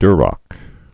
(drŏk, dyr-)